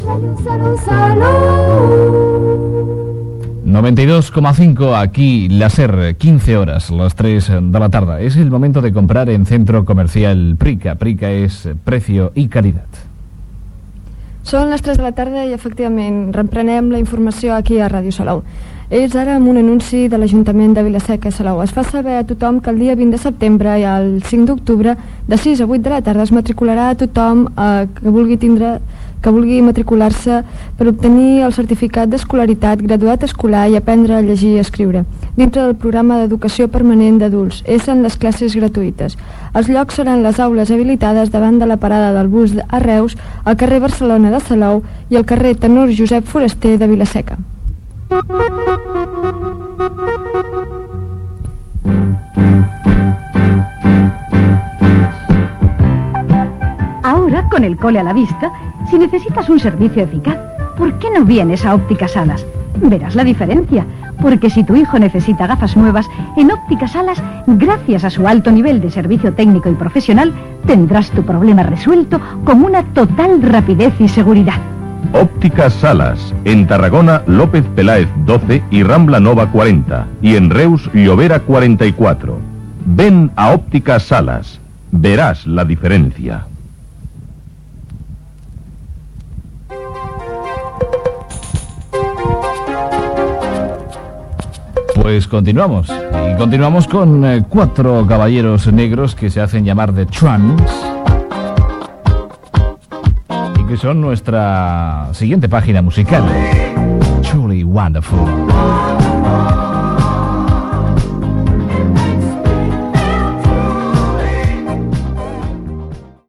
Indicatiu, publicitat, anunci Ajuntament de Vilaseca sobre el graduat escolar, publicitat i tema musical.
FM